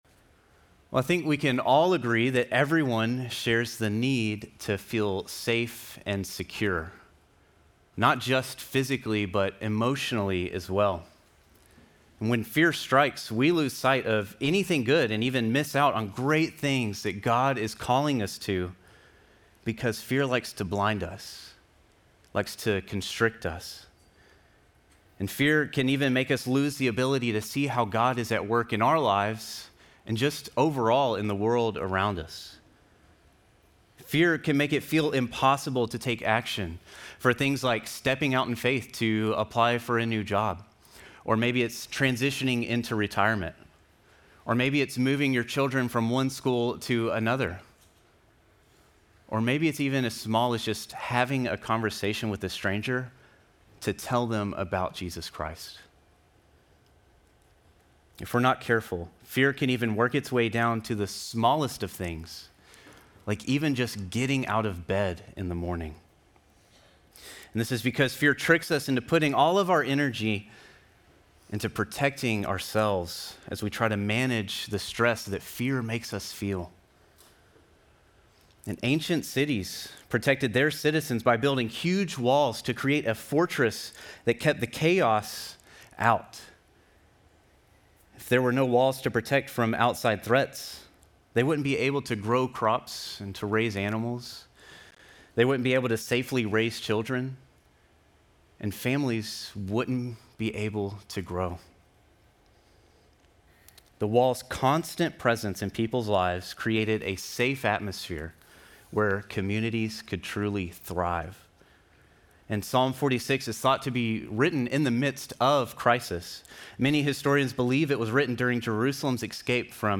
Sermon text: Psalm 46:1-11